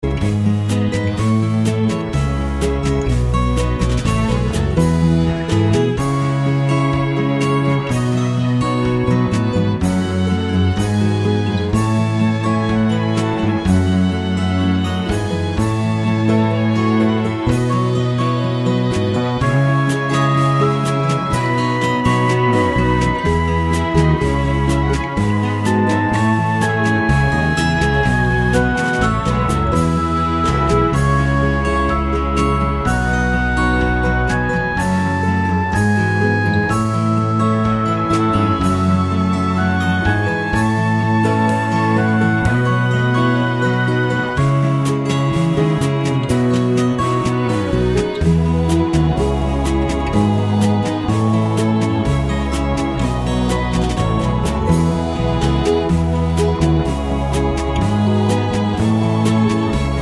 no Backing Vocals Country (Male) 2:25 Buy £1.50